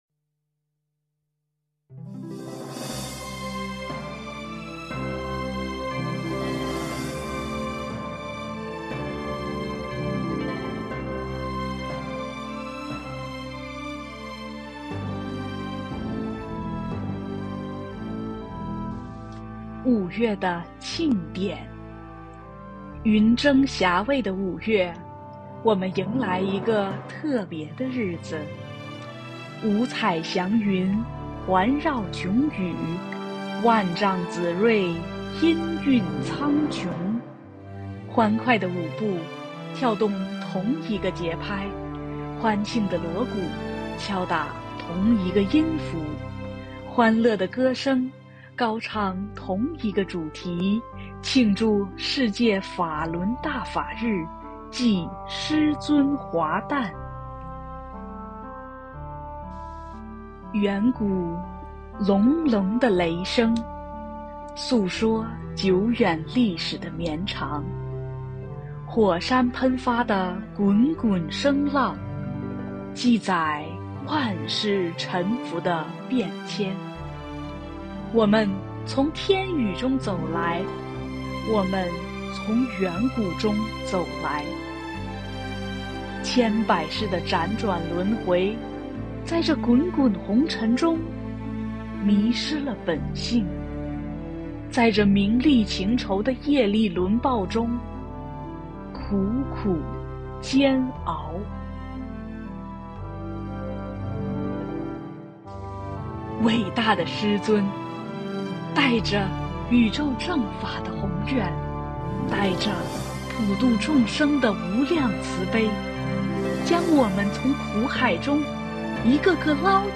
配樂詩朗誦（音頻）：五月的慶典
朗誦：